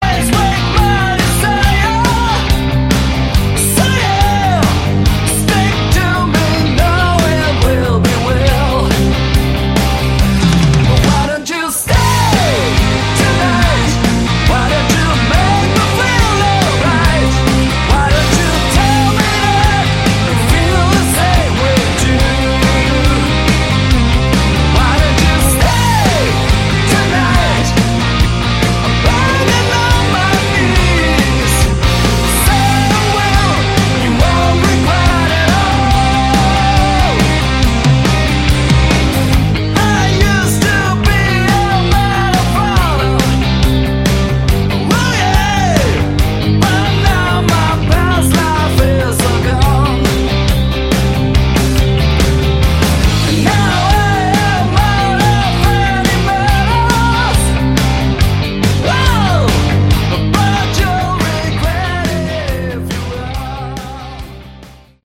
Category: Hard Rock
Vocals, Lead Guitar
Vocals, rhythm guitar
Drums
Vocals, Bass